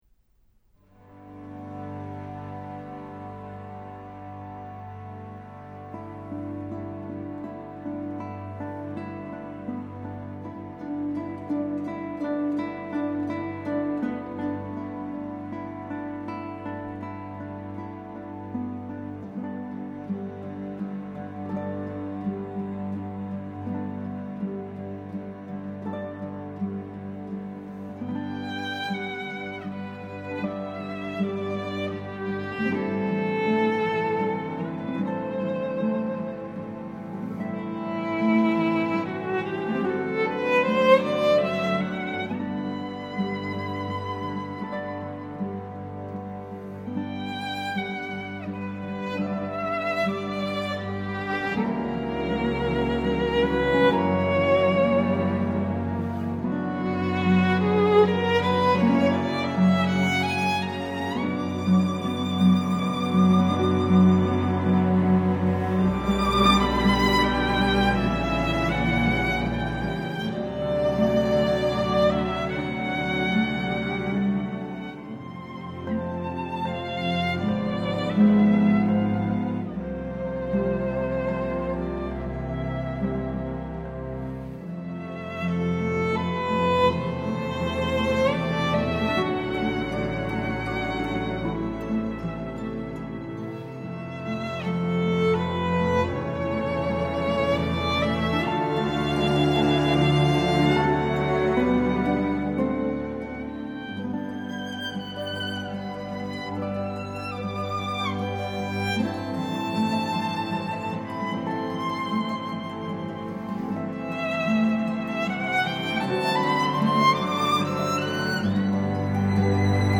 es un violinista prodigioso.